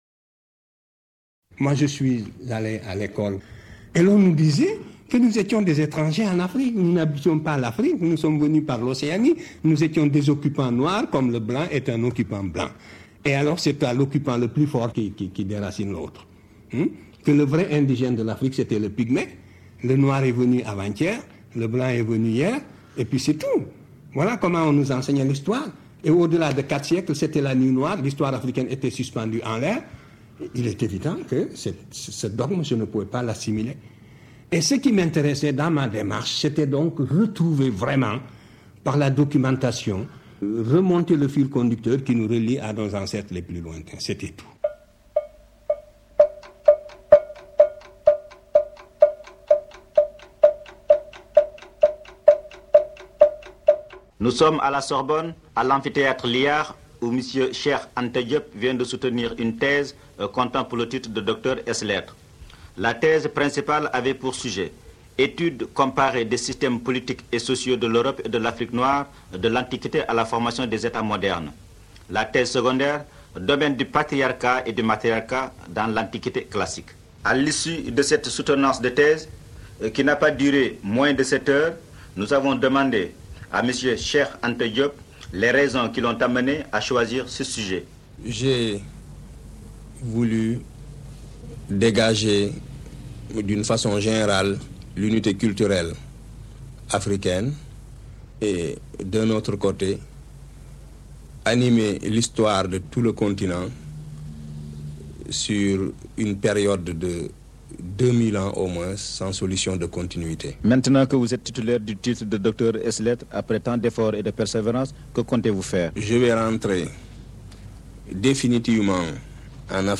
cad_interview1_son_mp3.MP3